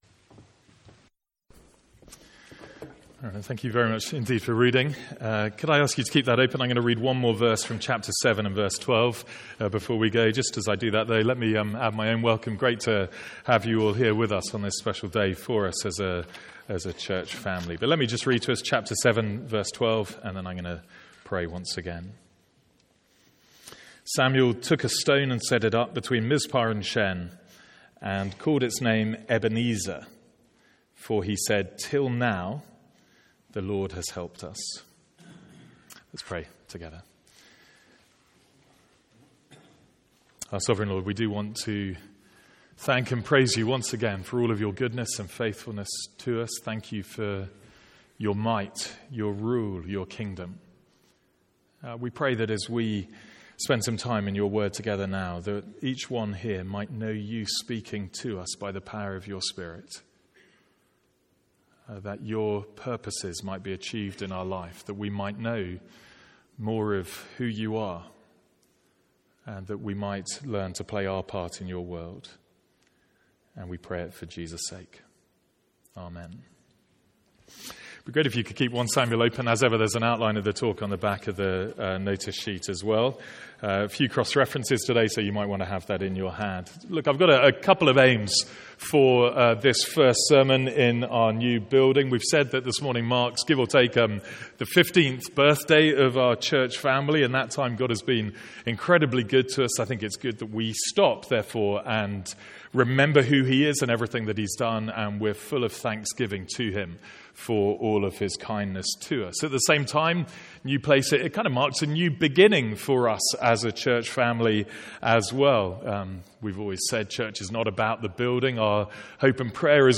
A one-off sermon of remembrance and looking forward as we move into our new building.